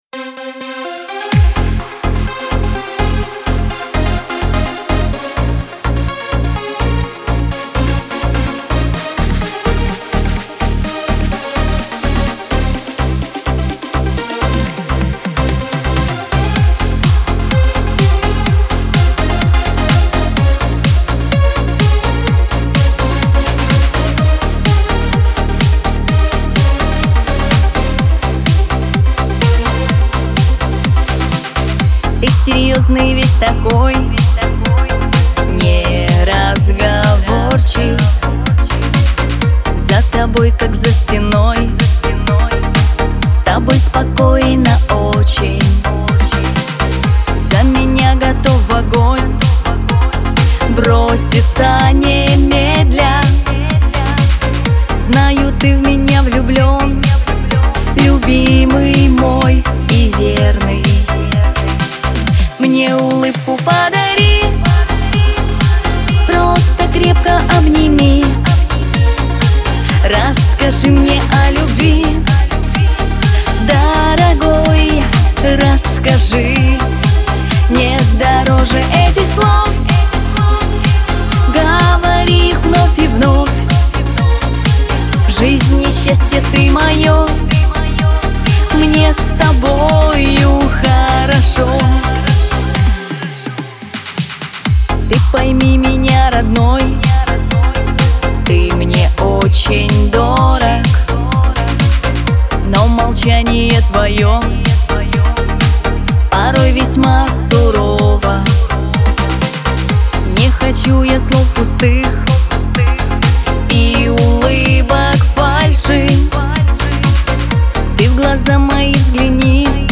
Шансон
Лирика
диско